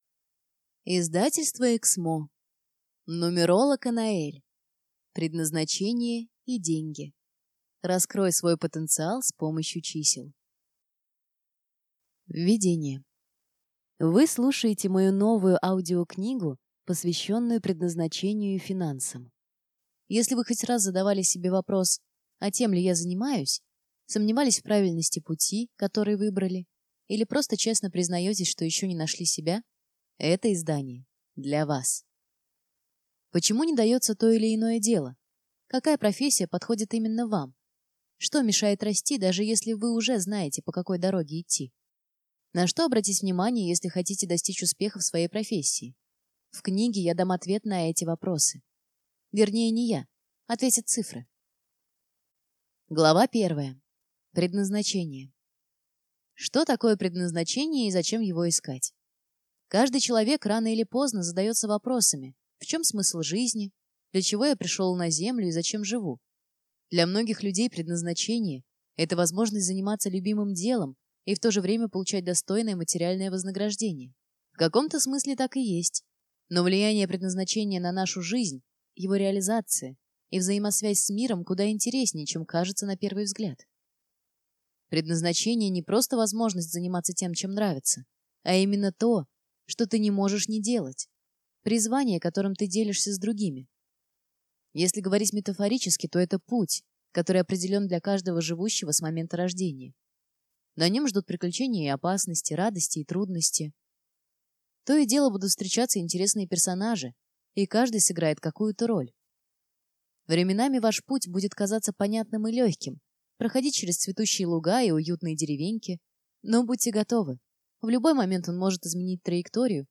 Аудиокнига Предназначение и деньги. Раскрой свой потенциал с помощью чисел | Библиотека аудиокниг